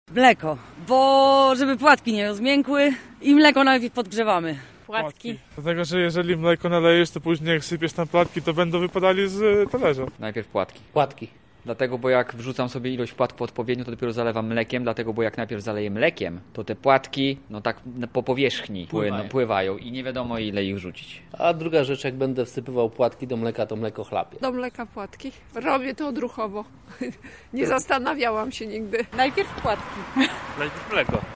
[SONDA] Najpierw mleko czy płatki?
W jego kontekście zadaliśmy mieszkańcom istotne pytanie.